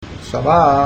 1_4_monk.mp3